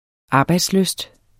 Udtale [ ˈɑːbɑjds- ]